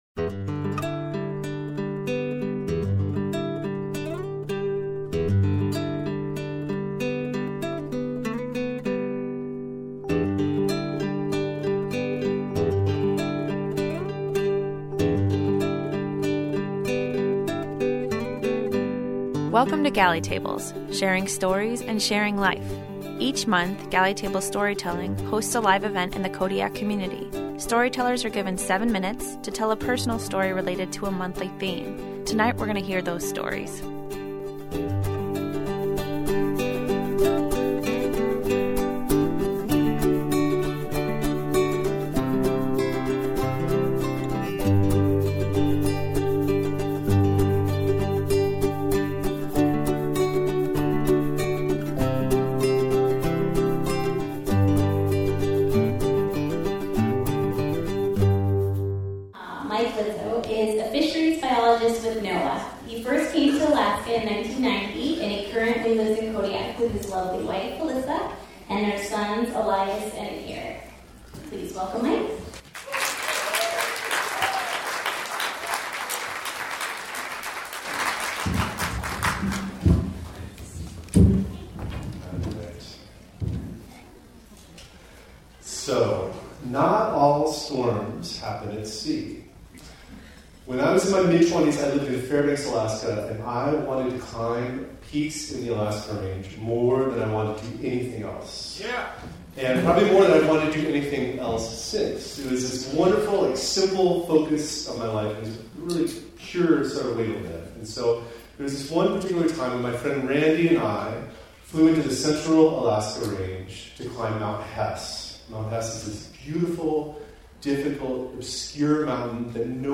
Listen as eight storytellers share stories around the theme “Storm Warning” in a live performance recorded on January 17, 2020 at the Kodiak Harbor Convention Center.